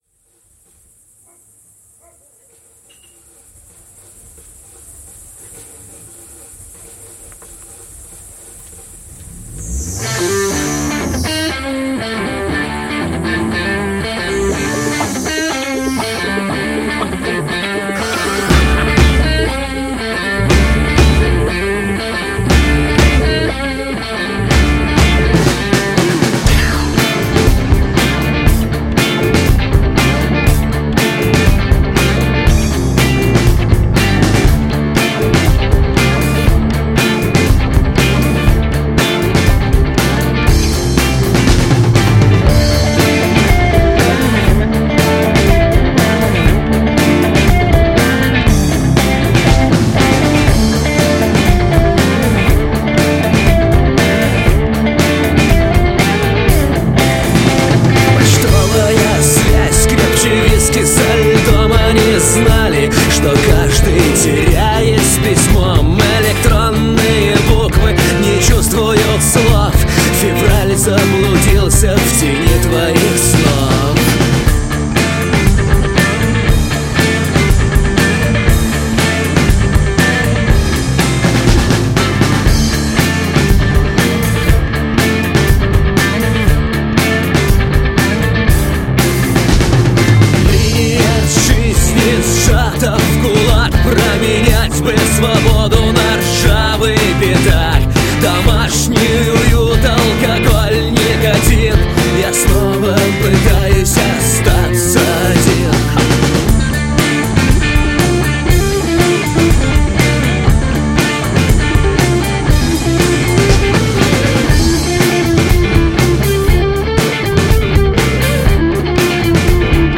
вокал, слова (кроме 08*), музыка,
ритм-гитара, акустическая гитара, бас-гитара.
соло-гитара.
ударные.